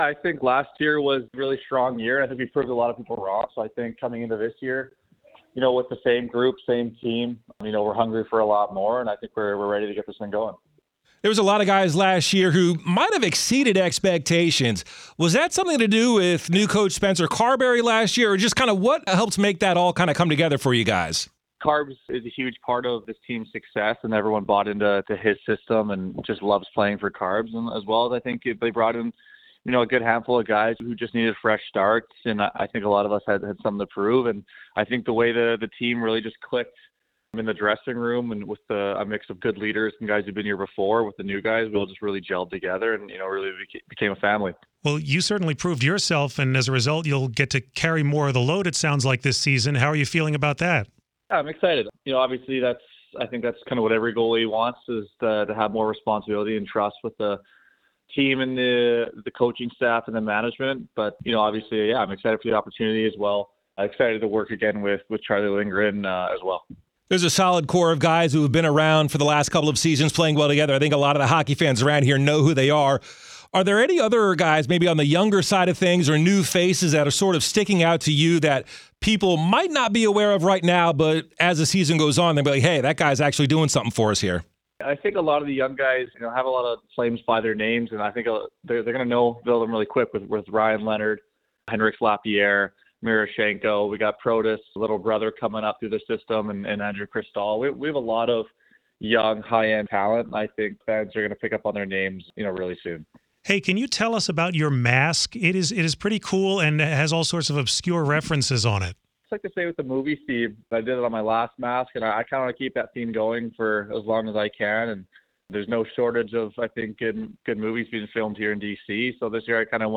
Washington Capitals goalie Logan Thompson previews the season on WTOP.